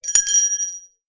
Bell ring
bell ding dinging ring ringing sound effect free sound royalty free Sound Effects